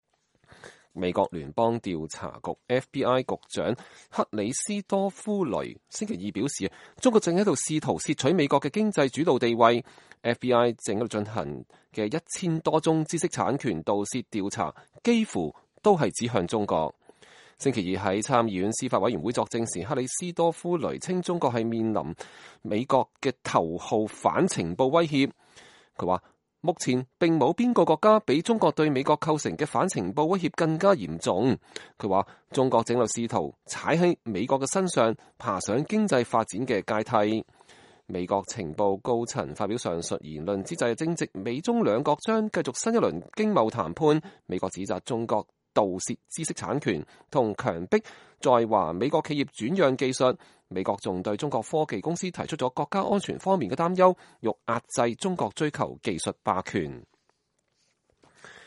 週二在參議院司法委員會作證時，克里斯托弗雷將這一威脅描述為“比我所能想到的任何反情報威脅都更深刻、更多樣化、更令人惱火、更具挑戰性、更全面和更令人擔憂。”